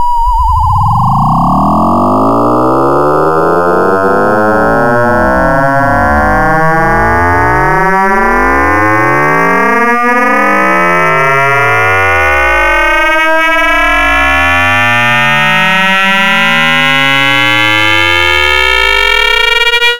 16-RM-FM-SIN-02.mp3